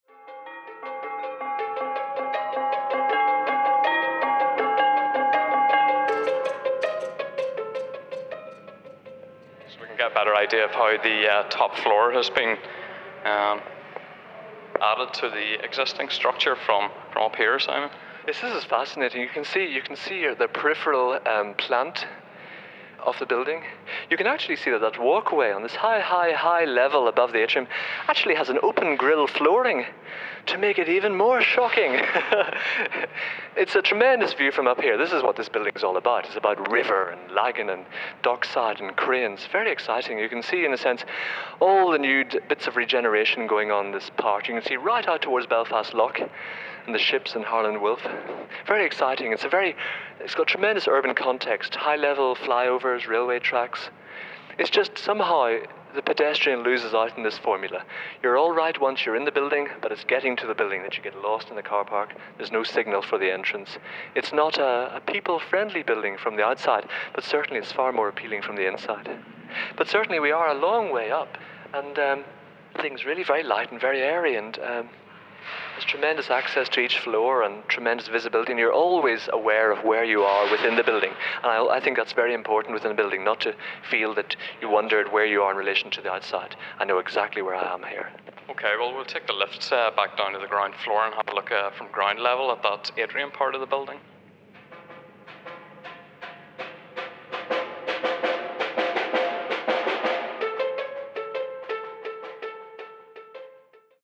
Installation with 13 minute audio piece, portable radio
This piece was made for 'Barrage', a site-specific exhibition of sound art that took place at a contemporary office building in Belfast.
The interview was edited with music (John Cage's 'Second Construction', 1940) in the style of a radio documentary and replayed through a portable radio placed on the counter of a disused coffee bar in the building's central atrium space.